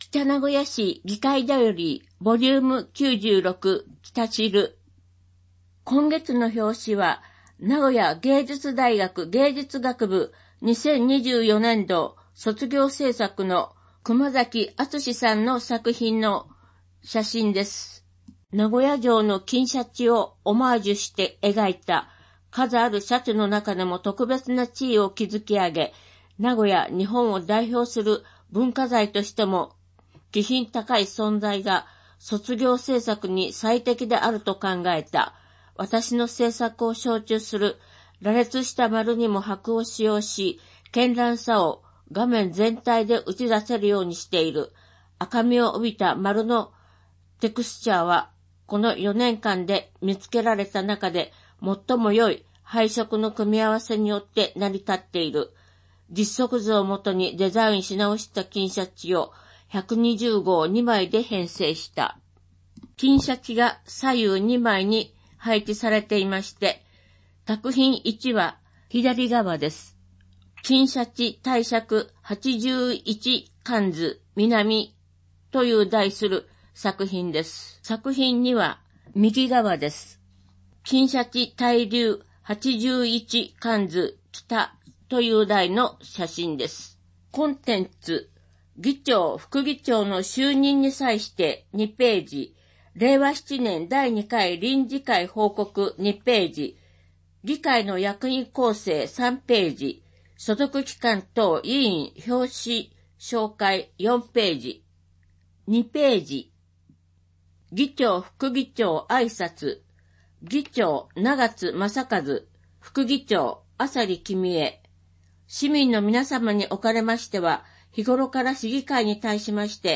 議会だより『きたしる』音声版 第96号